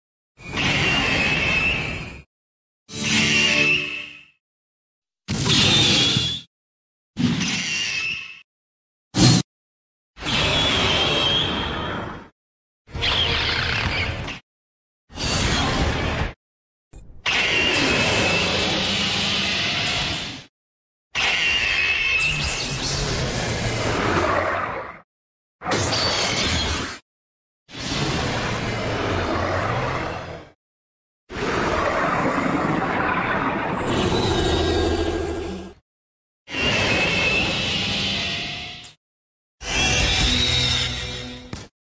Grantella_roars.ogg